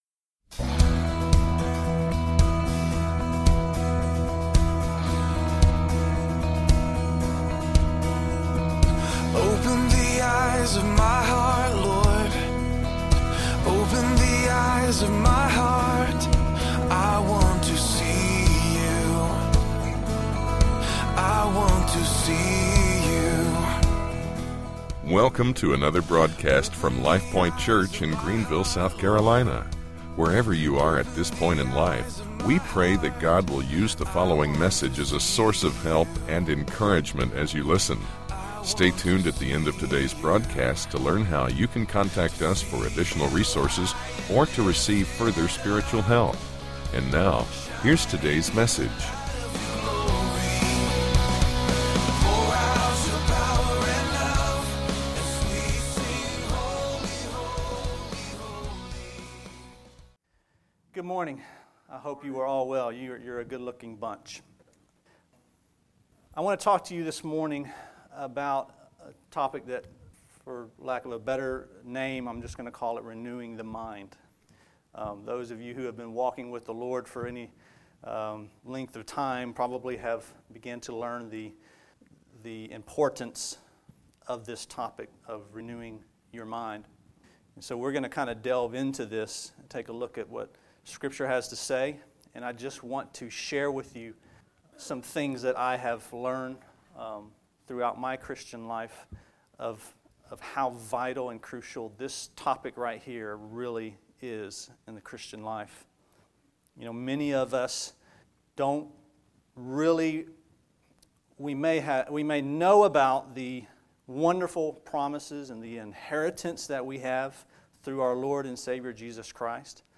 SUNDAY SERMON: RENEWING YOUR MIND